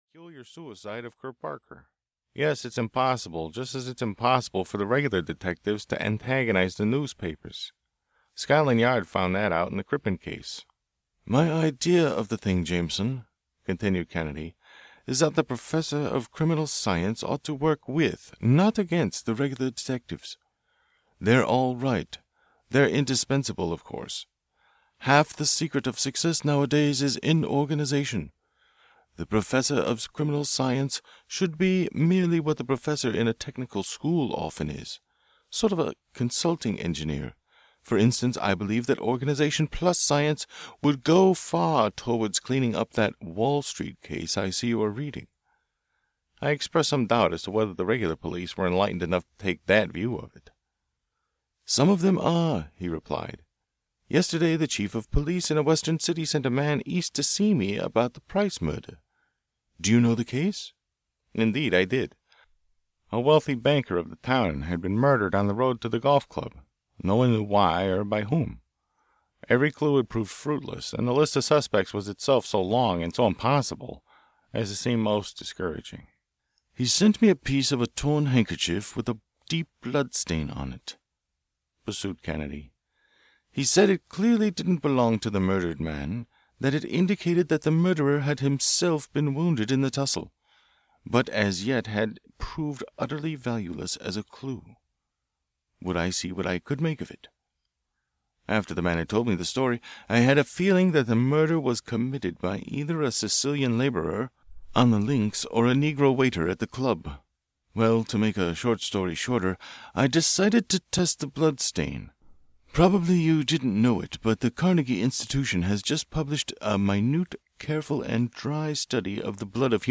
The Silent Bullet (EN) audiokniha
Ukázka z knihy